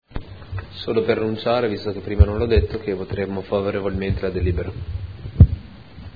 Seduta del 2 luglio.
Dichiarazioni di voto